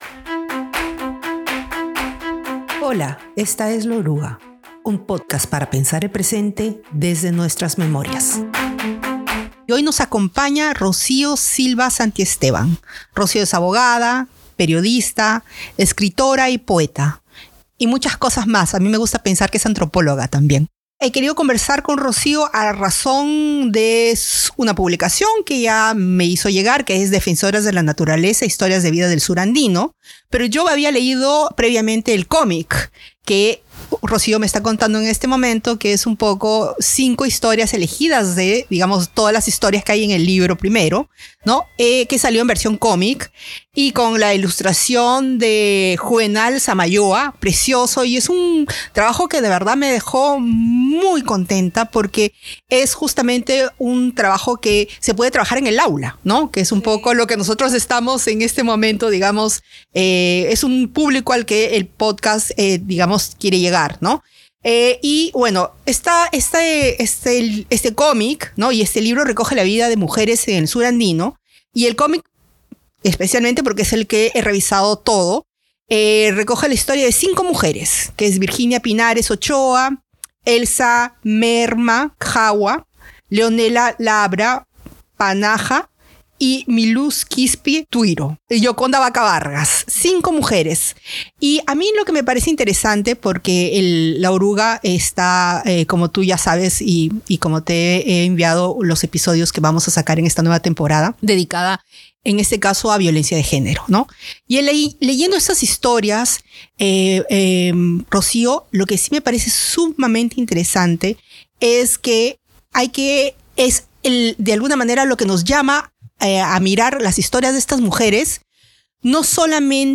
Conversando con Rocío Silva-Santisteban
En este episodio conversamos con la escritora, activista y feminista Rocío Silva Santisteban sobre diversas mujeres en el corredor minero, para quienes la violencia parece ser un continuo. Han vivido un pasado marcado por el conflicto armado interno y hoy deben luchar contra la contaminación de su entorno. No solo enfrentan al Estado y a la minería a través de protestas legítimas, sino que también deben romper con jerarquías y roles de género en sus comunidades.